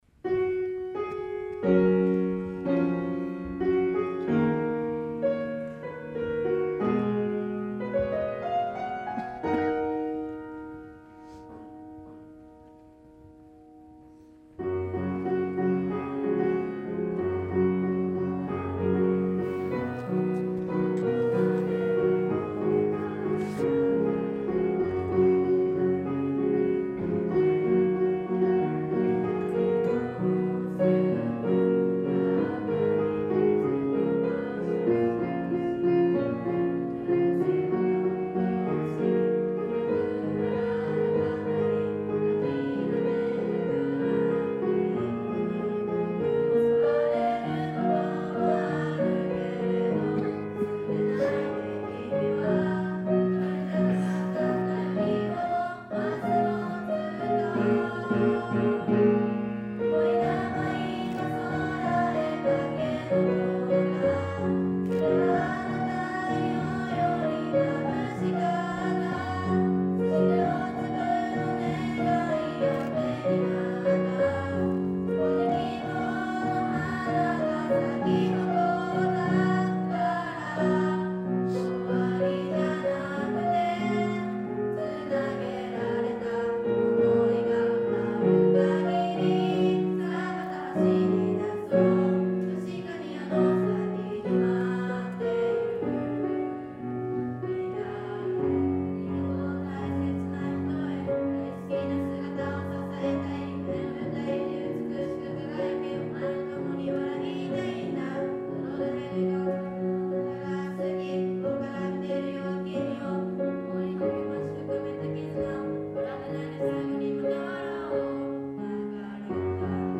クラス合唱　音楽科発表
平成30年度 学習発表会 2-2合唱